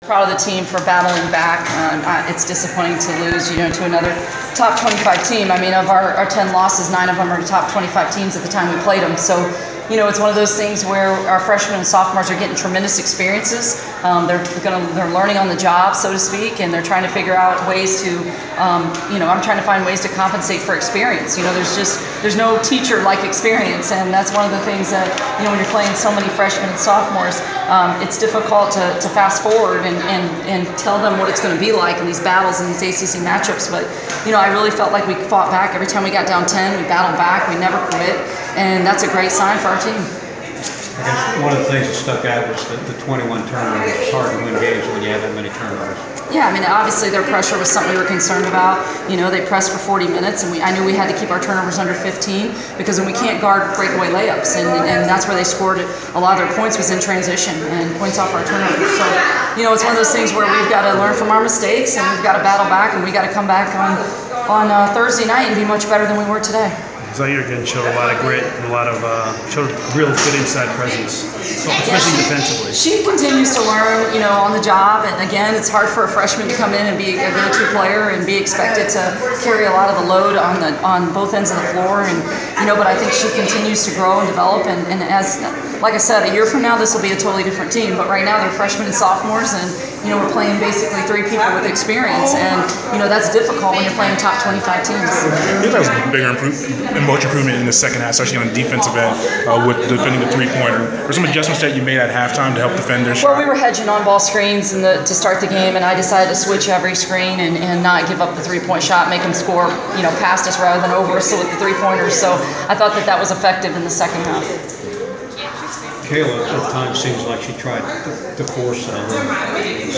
Inside the Inquirer: Postgame interview